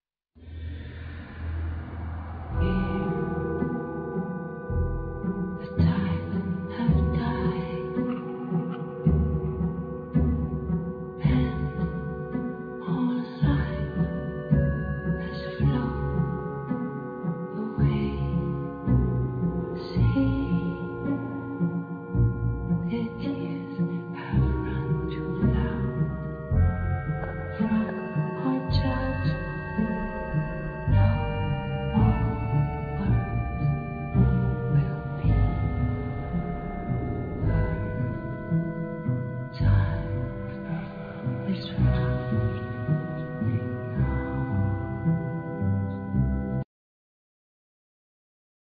Musicians
Vocal
Piano,Synthe Solo
Lyricon
Bandoneon
Ney
Drums
Bass
Percussion,Vocal